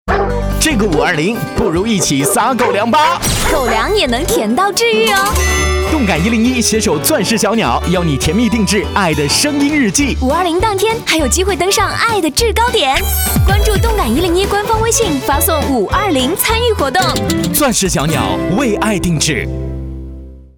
时段硬广：15秒+5秒宣传片（5.01-5.10，10天）
钻石小鸟——动感101广播广告投放音频: